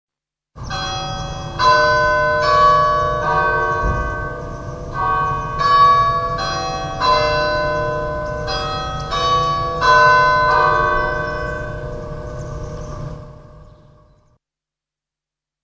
belmontbells.mp3